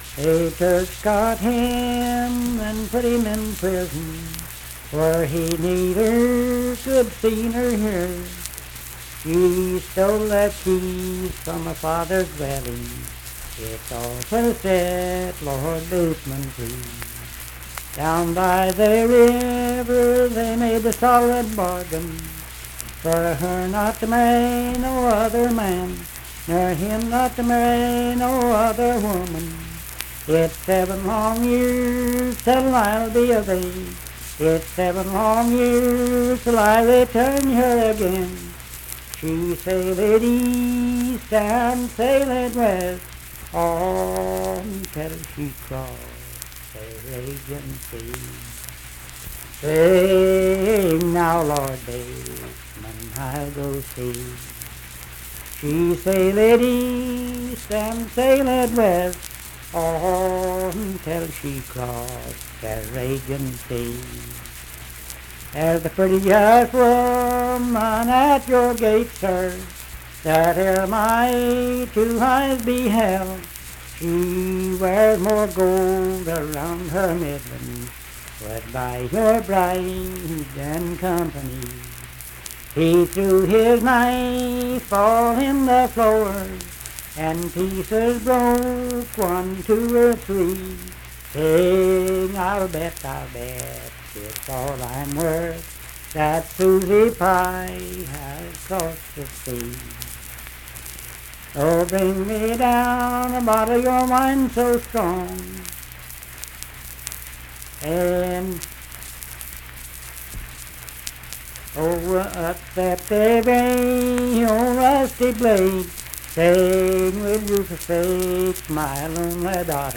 Unaccompanied vocal music performance
Voice (sung)
Sutton (W. Va.), Braxton County (W. Va.)